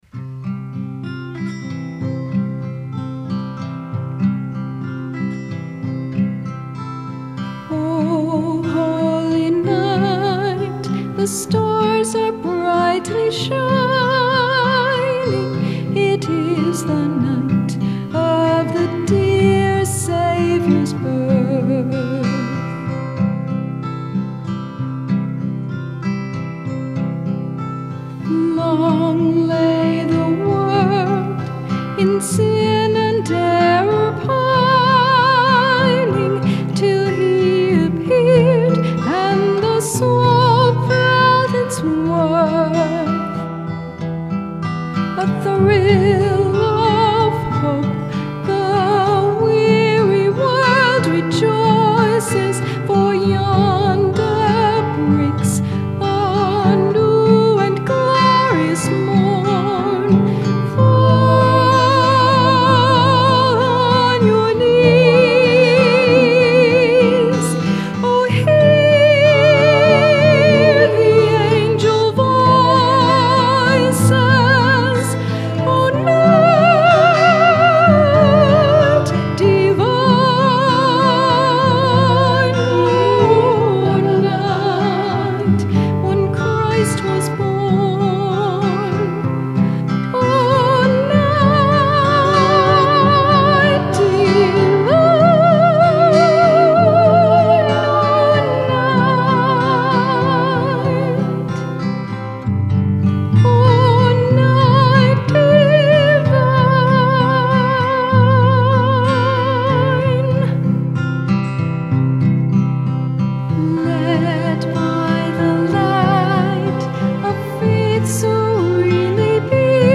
p.s. you can listen to my rendition of “O Holy Night” here (my husband is playing the guitar):
Lovely singing!